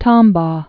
(tŏm), Clyde William 1906-1997.